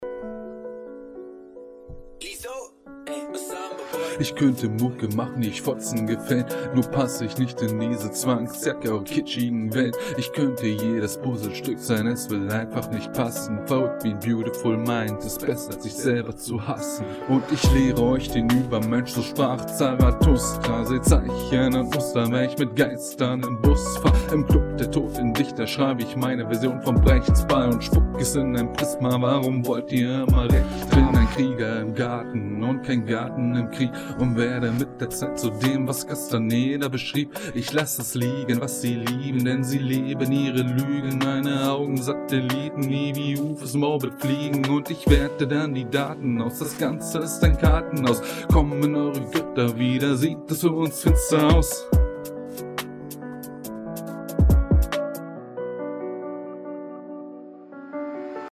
(Schreibaufnahme)